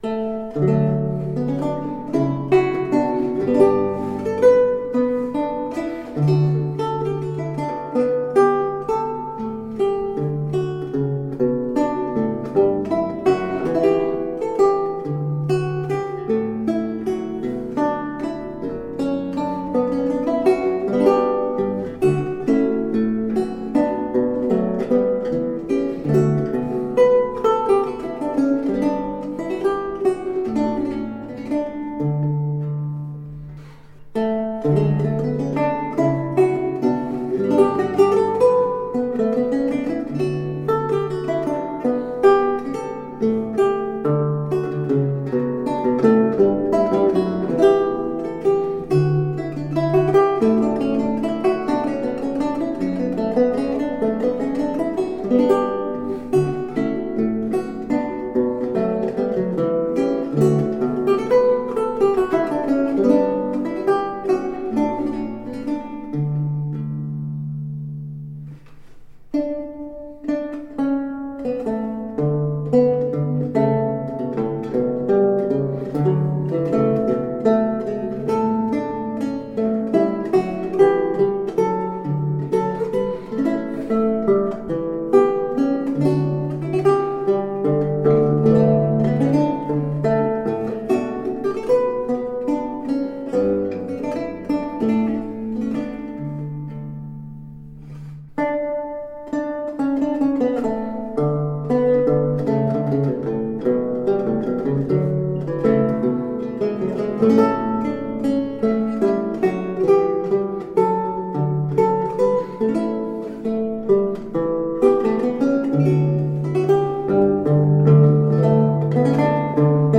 Lute music of 17th century france and italy
Classical, Baroque, Renaissance, Instrumental
Lute